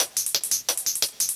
Index of /musicradar/ultimate-hihat-samples/175bpm
UHH_ElectroHatD_175-04.wav